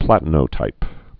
(plătn-ō-tīp)